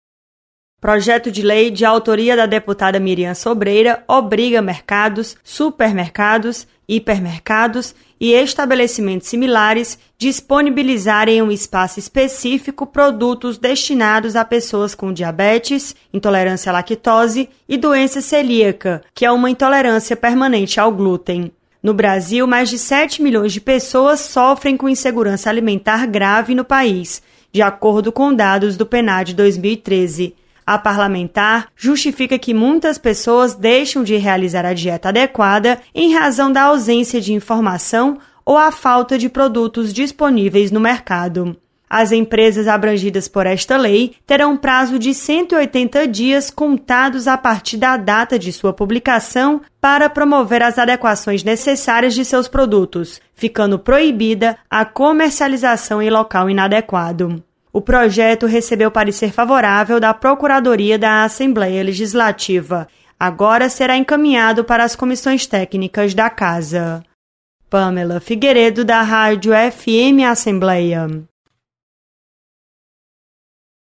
Projeto visa destinar espaços reservados em supermercados para produtos voltados aos diabéticos e alérgicos. Repórter